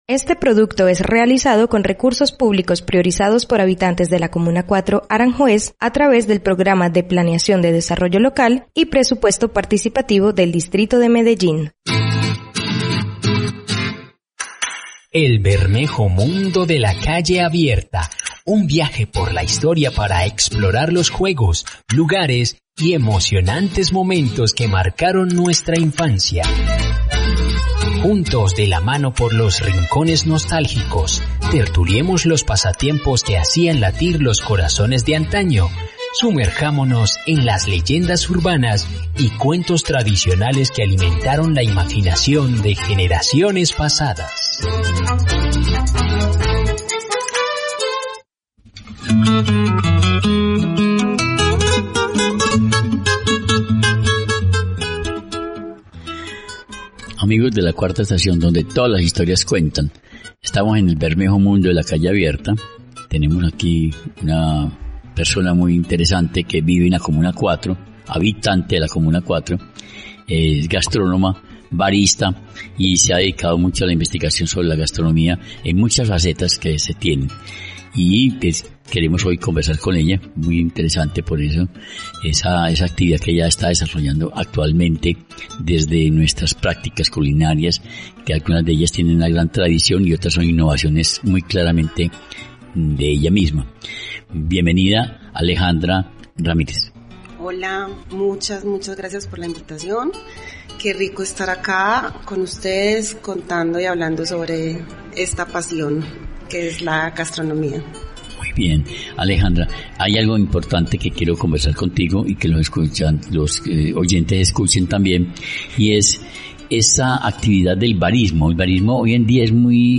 En esta charla, nos enseña cómo preparar salsas deliciosas y nos cuenta sobre el proyecto actual de sus salsas, así como sus futuros proyectos.